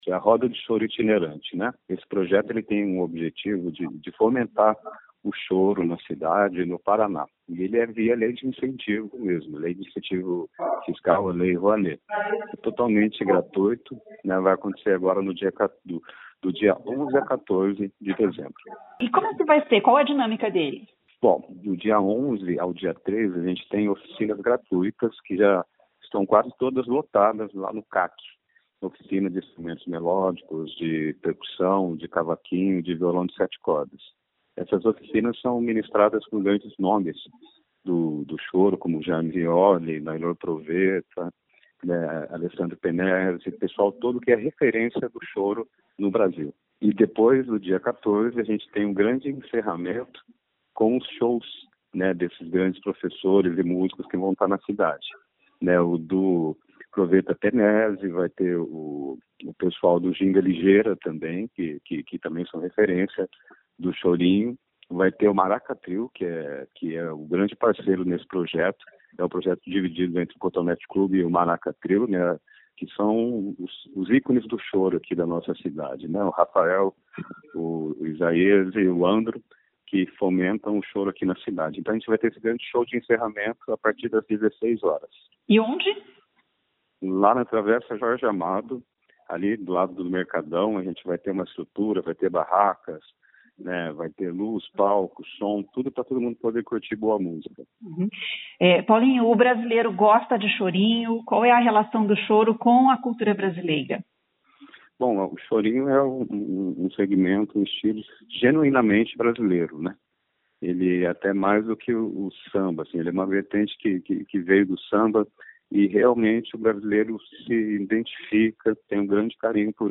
O produtor cultural